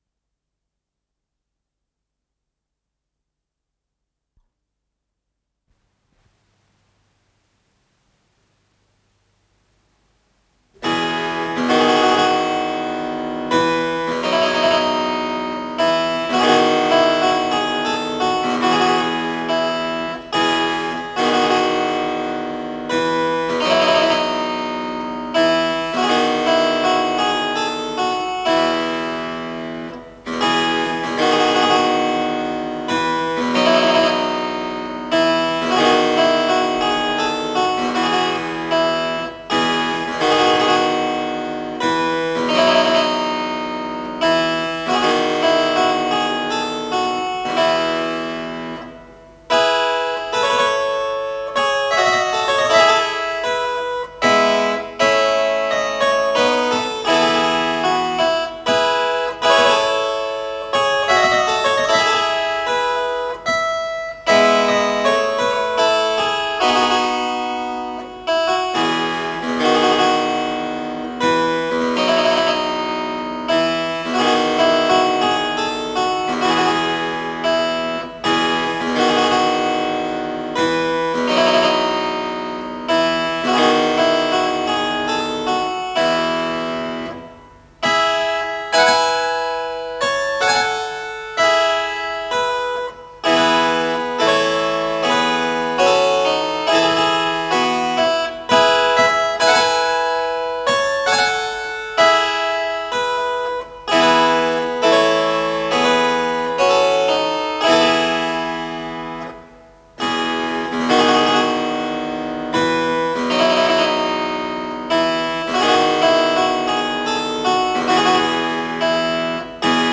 ИсполнителиИгорь Жуков - клавесин